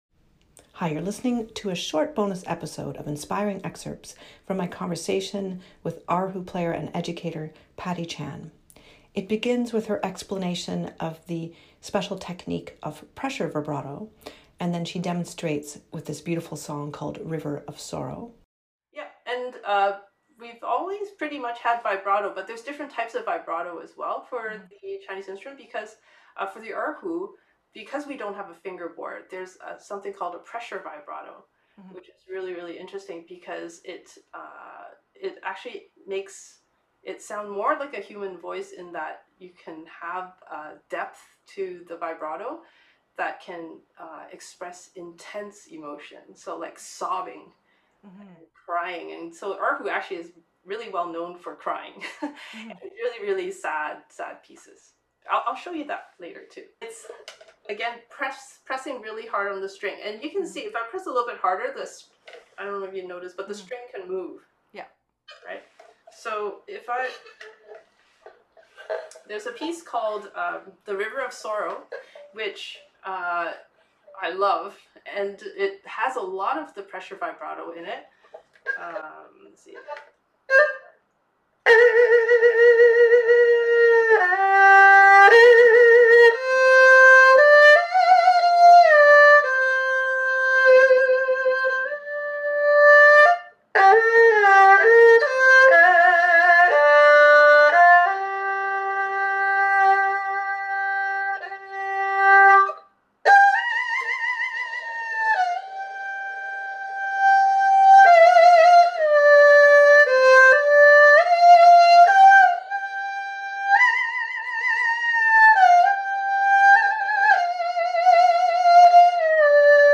Conversations with Musicians
This short bonus episode in my Inspiring Excerpts series has clips from E4 S1 with erhu player